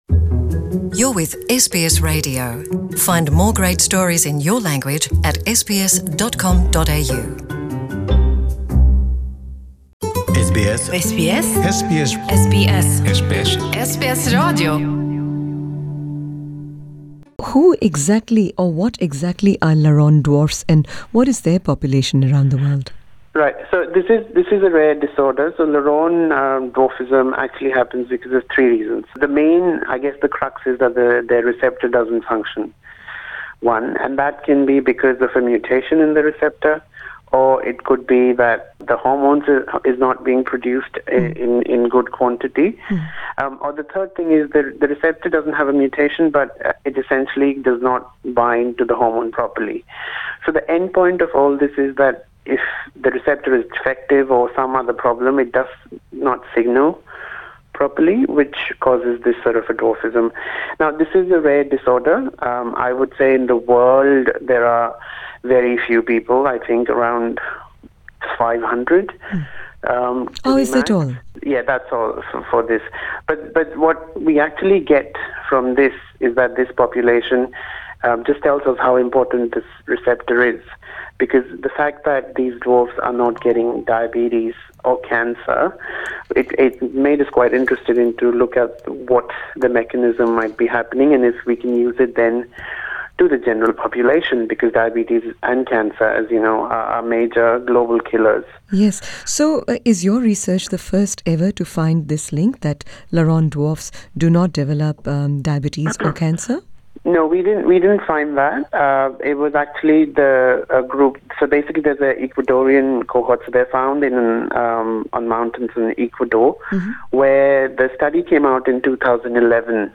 In an interview to SBS Punjabi